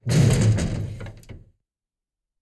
4aef571f59 Divergent / mods / Soundscape Overhaul / gamedata / sounds / ambient / soundscape / underground / under_11.ogg 62 KiB (Stored with Git LFS) Raw History